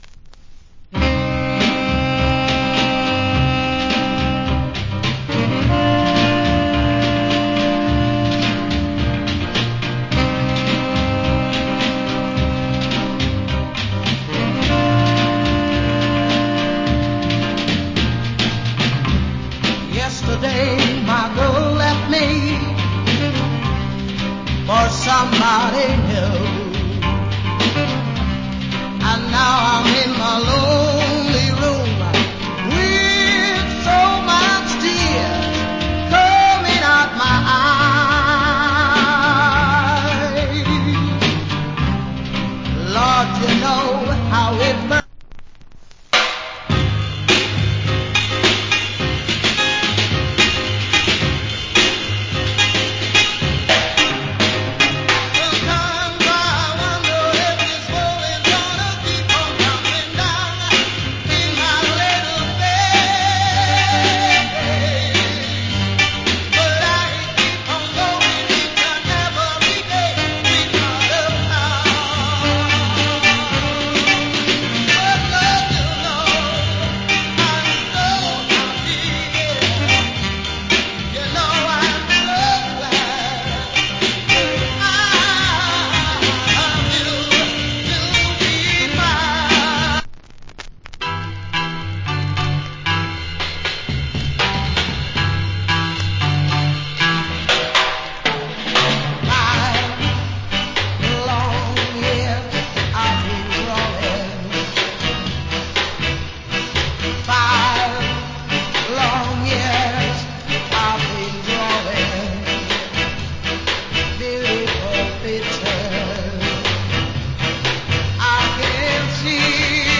Top Ska Vocal. 80's.